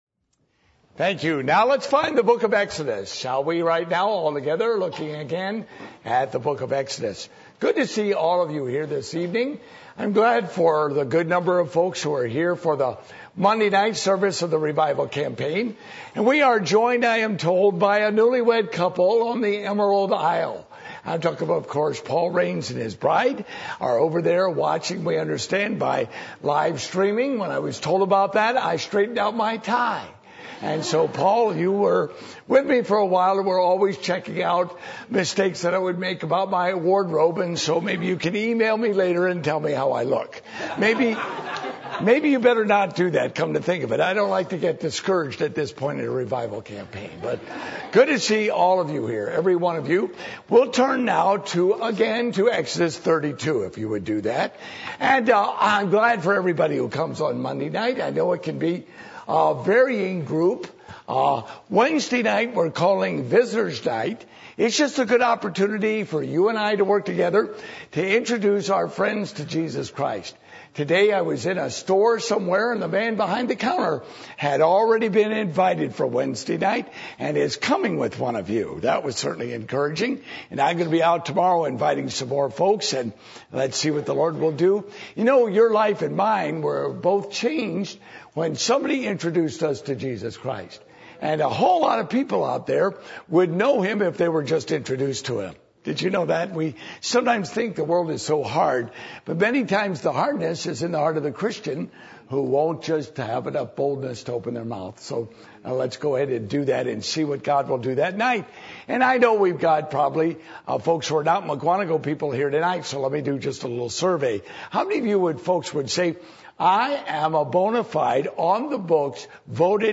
Service Type: Revival Meetings